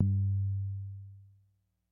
标签： MIDI-速度-16 FSharp4 MIDI音符-67 罗兰-SH-2 合成器 单票据 多重采样
声道立体声